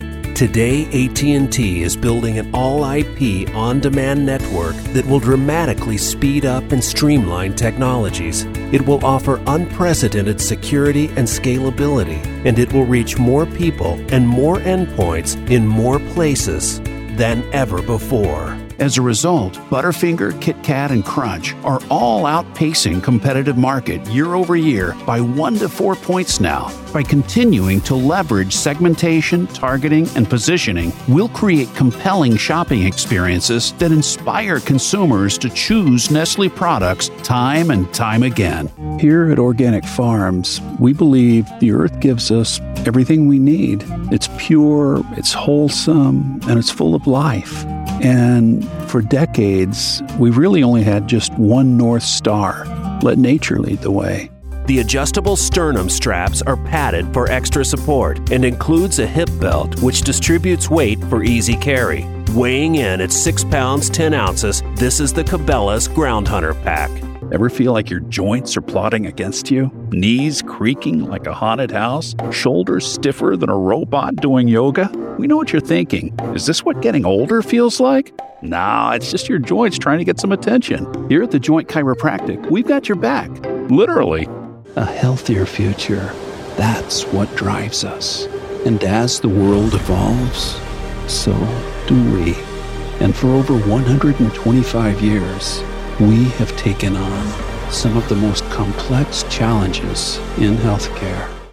Engels (Amerikaans)
Natuurlijk, Opvallend, Veelzijdig, Vriendelijk, Warm
Corporate